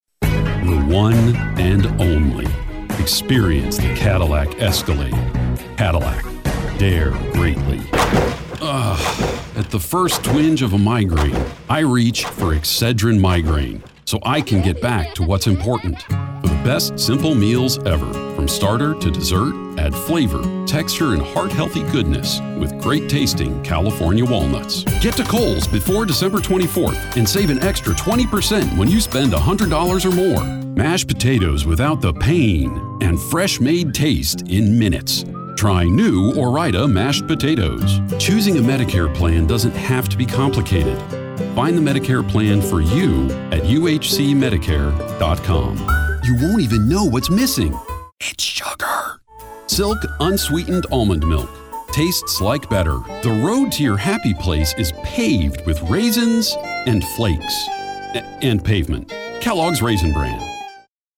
Male
Adult (30-50)
My voice is deep, resonant, authoritative, friendly, sonorous, confident, soothing, warm, understandable, knowledgeable, honest and confident
Radio Commercials
Commercial Compilation
0410Commercial-1_Demo.mp3